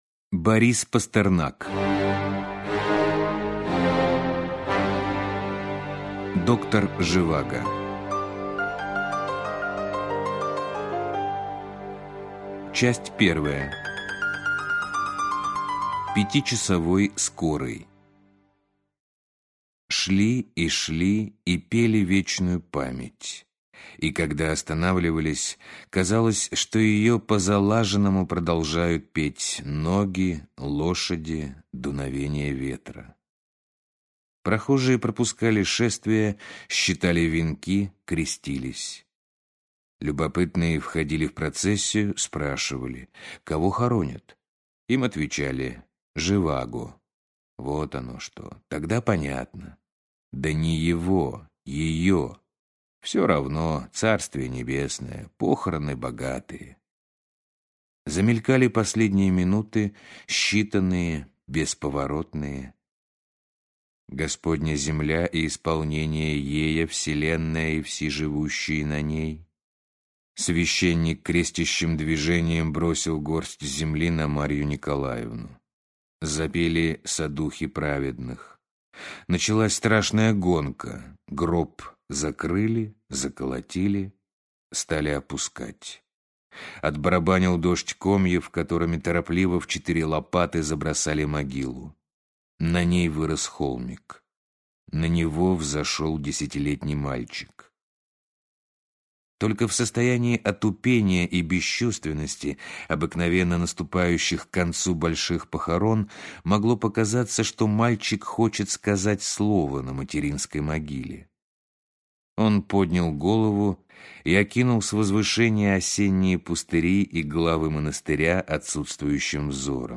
Аудиокнига Доктор Живаго | Библиотека аудиокниг